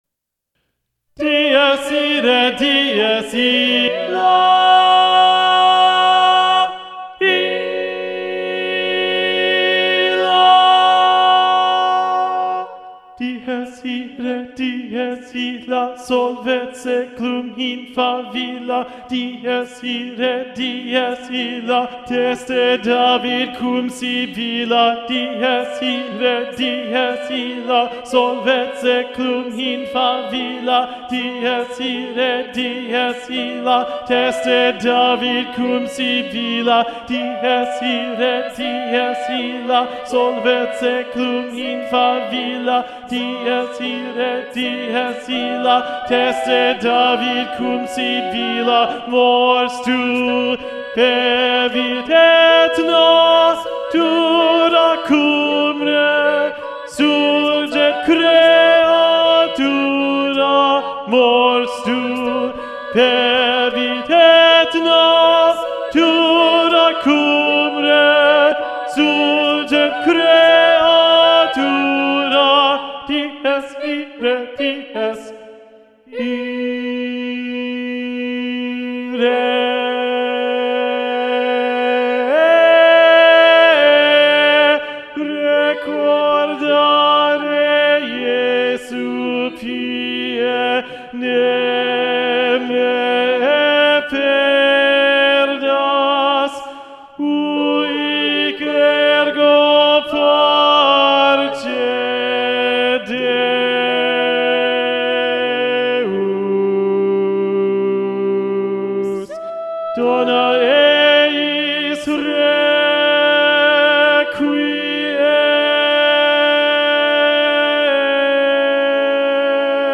- Œuvre pour chœur à 8 voix mixtes (SSAATTBB)
SATB Tenor 1 Predominant